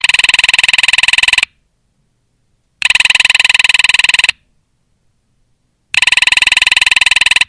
Kategorien Telefon